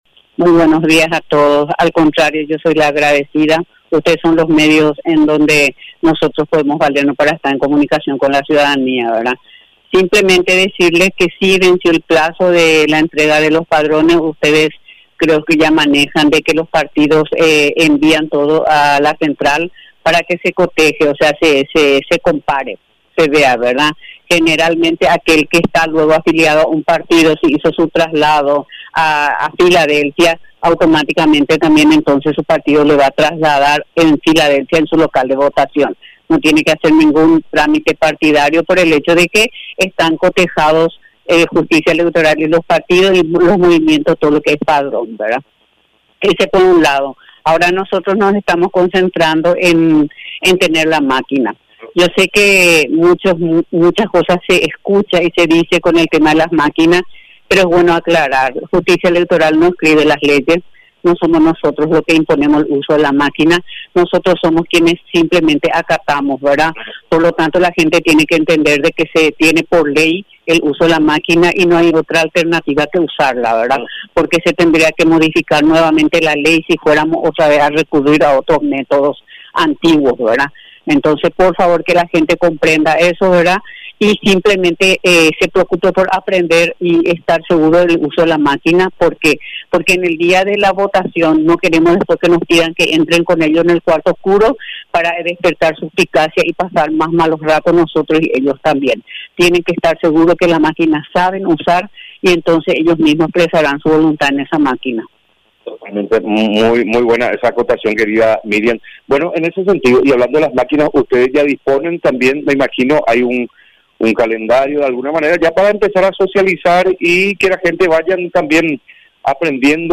Entrevistas / Matinal 610